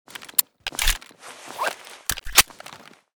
colt_reload.ogg